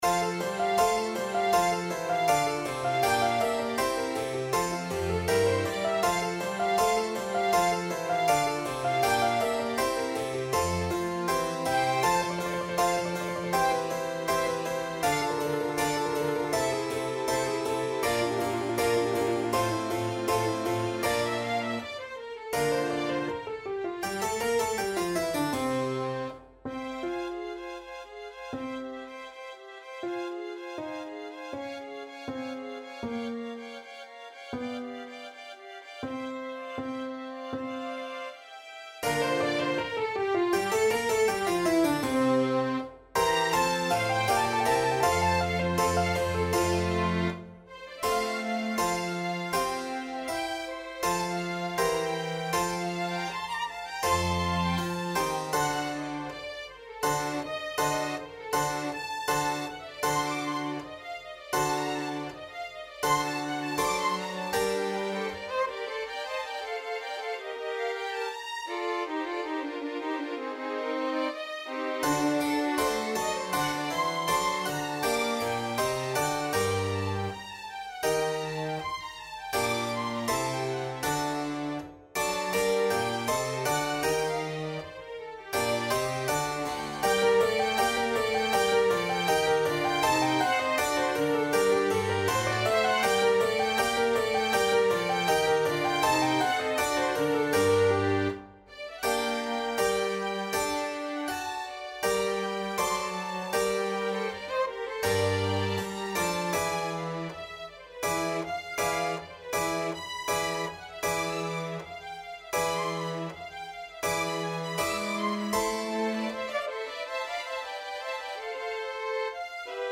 Time Signature: 4/4
Score Key: F major (Sounding Pitch)
Tempo Marking: I: Allegro = 80